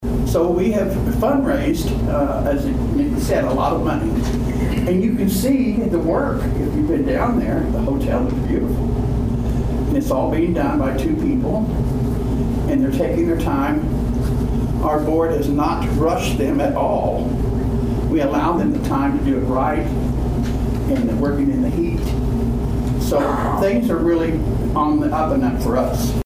The Dewey City Council met for the first time in the month of September on Tuesday night at Dewey City Hall.